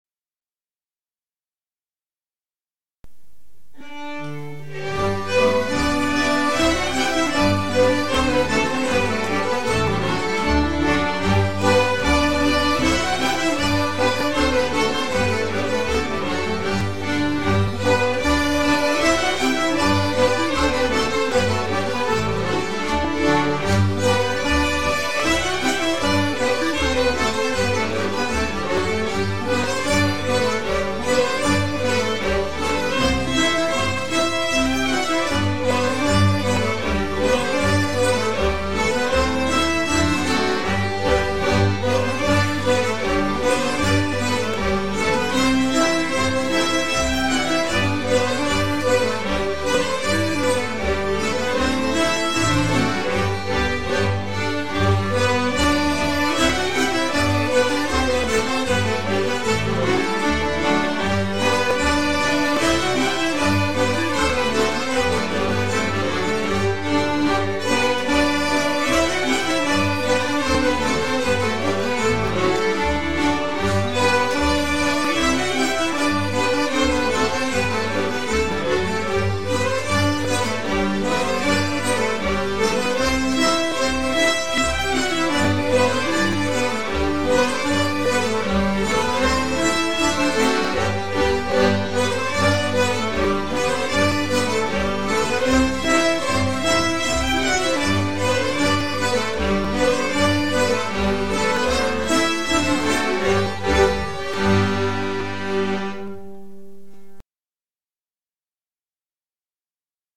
Allspelslåtar Slottsskogsstämman 2025